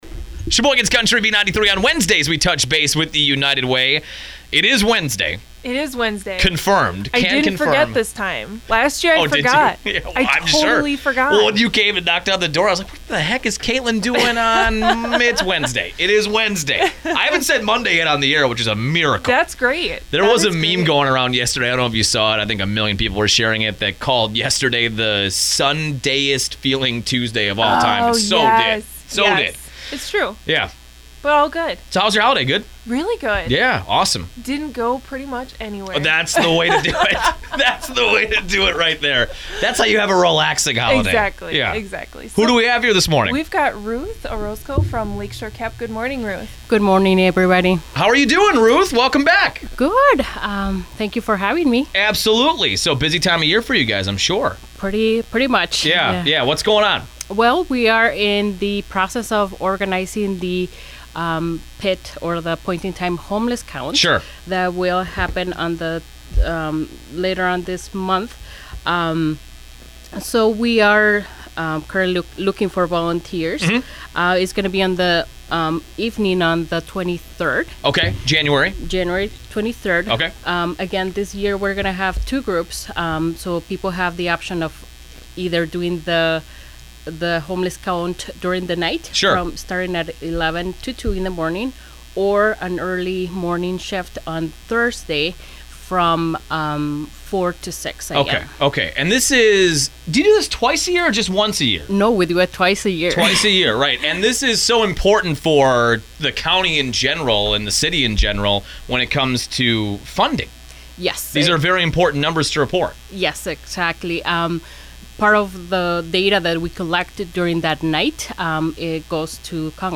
This week on the radio: Lakeshore Community Action Program
Thank you to Midwest Communications for sponsoring the weekly radio spot on WHBL and B93 Sheboygan’s Country Radio Station!